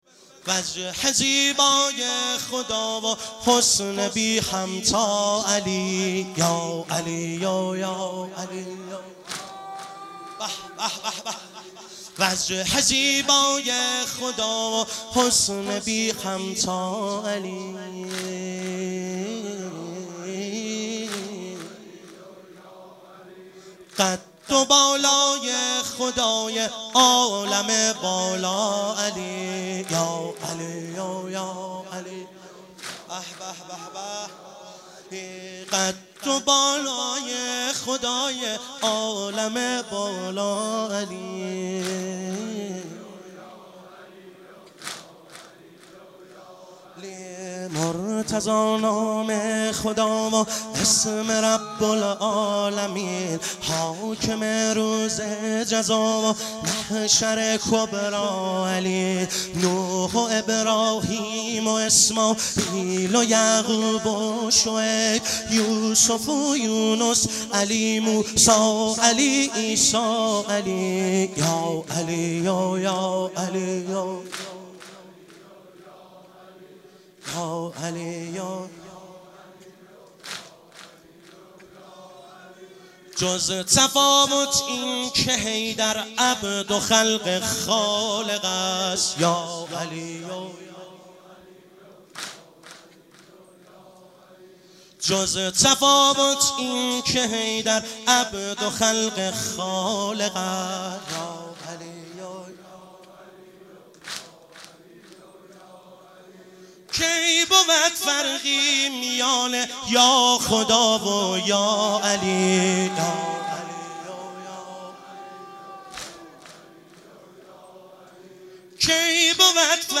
مشهد الرضا - واحد - 8 - 1395